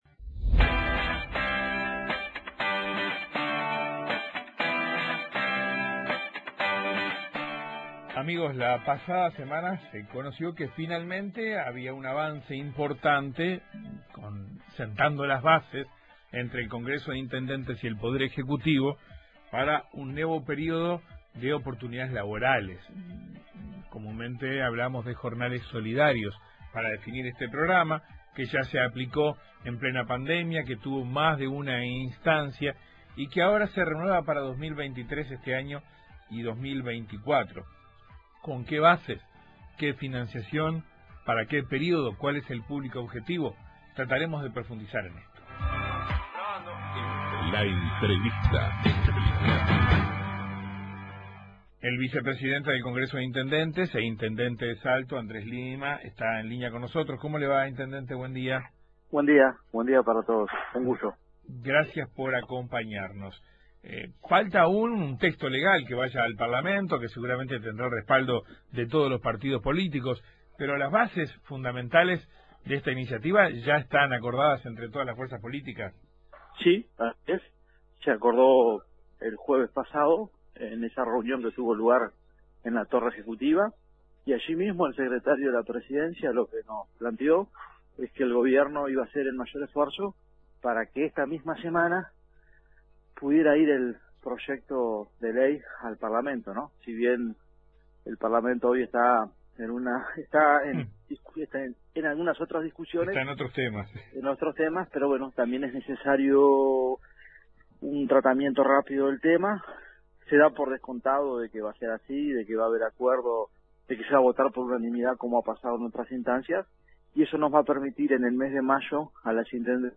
Es factible que se vuelvan a aplicar también los criterios de preservar cupos para personas afro, trans y con discapacidad, explicó Lima en Informativo Uruguay.